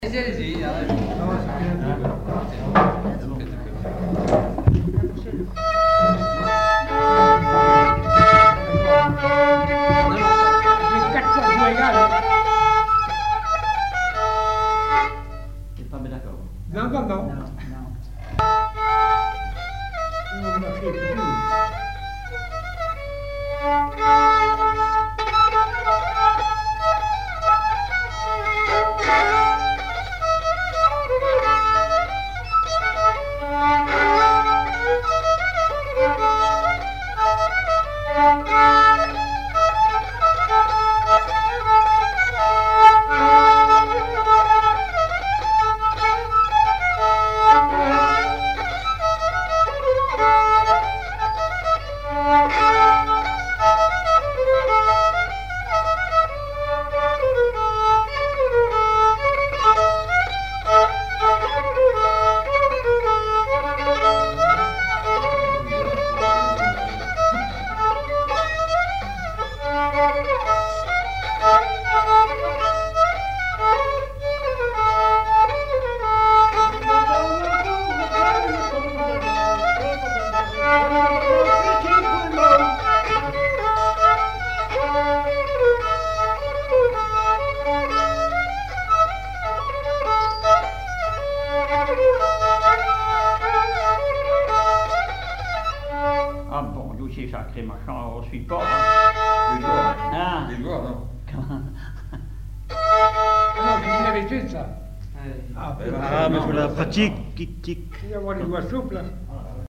branle
Chants brefs - A danser
chansons populaires et instrumentaux
Pièce musicale inédite